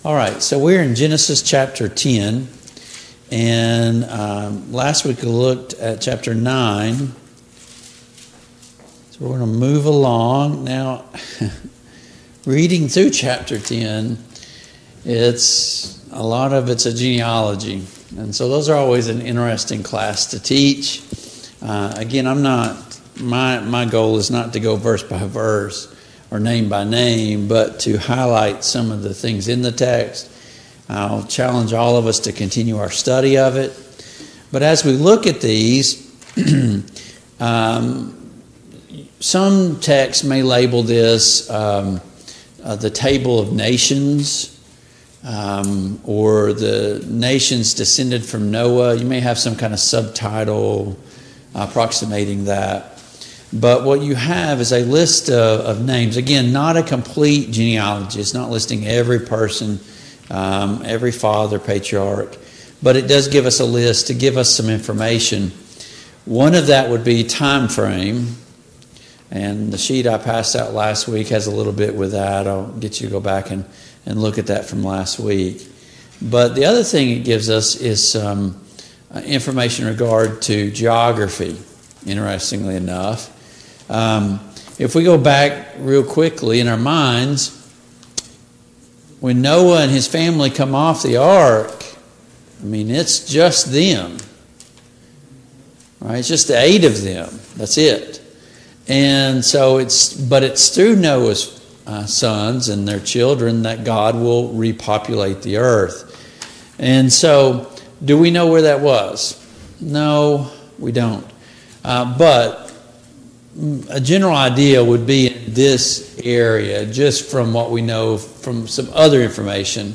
Genesis 10 Service Type: Family Bible Hour Topics: The Table of Nations « Remember Lot’s Wife 24.